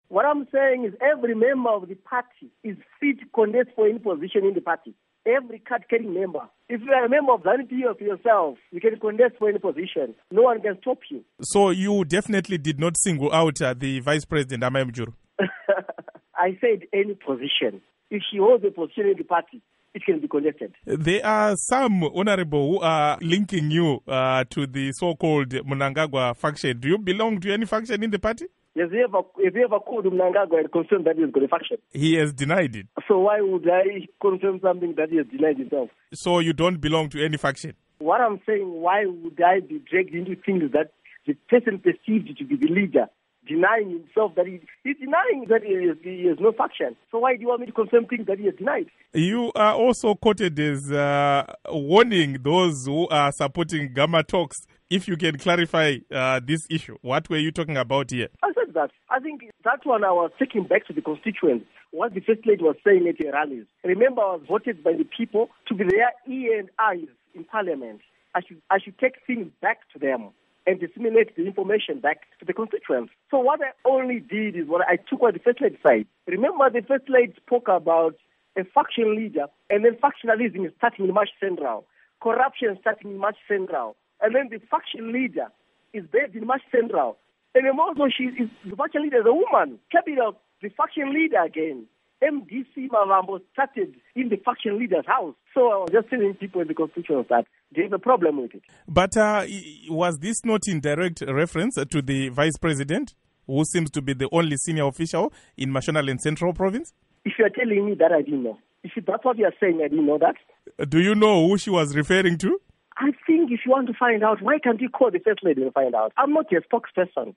Interview With Justice Mayor Wadyajena